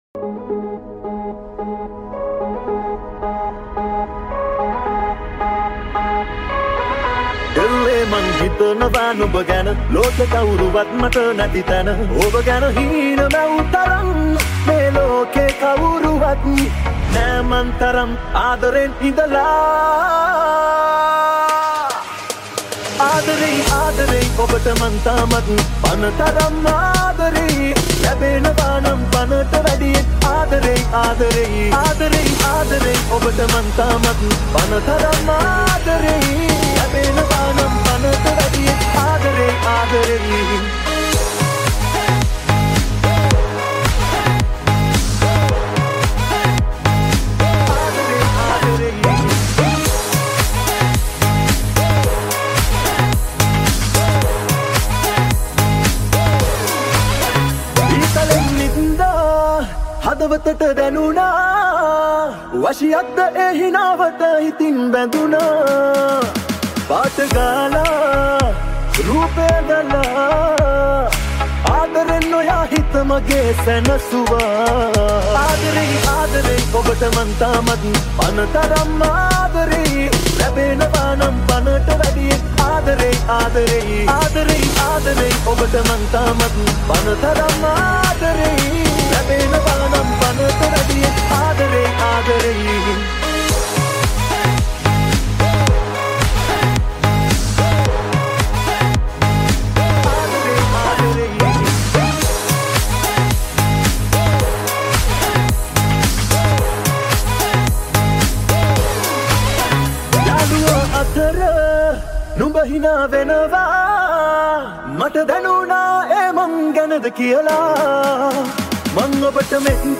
High quality Sri Lankan remix MP3 (2.8).